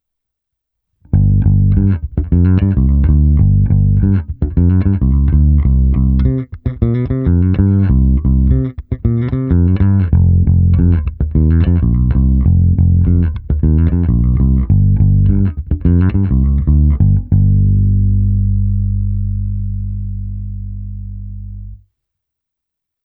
V řetězci dále byl aktivní preamp Darkglass Harmonic Booster, kompresor TC Ellectronic SpectraComp a preamp Darkglass Microtubes X Ultra se zapnutou simulací aparátu.
Cívky 1 a 3 - zvuk ala '70 Jazz Bass - basy +30, středy +10, výšky +30